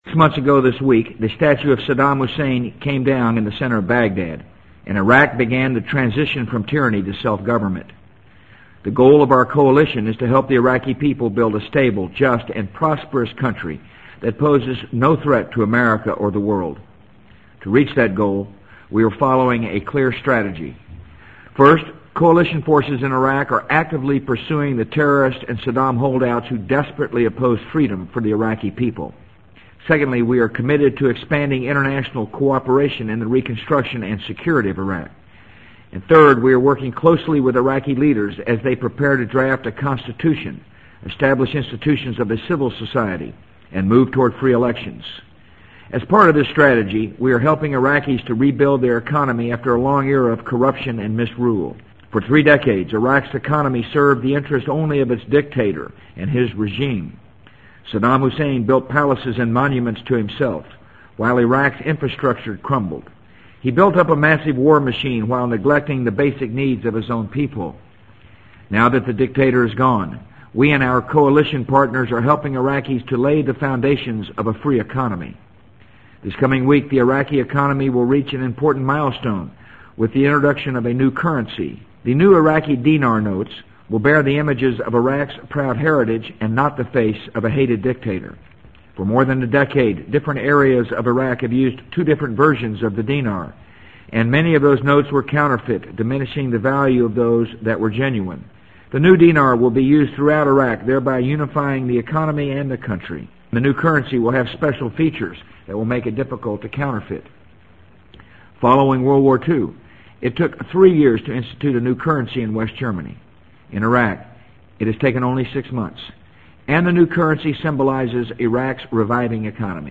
【美国总统George W. Bush电台演讲】2003-10-11 听力文件下载—在线英语听力室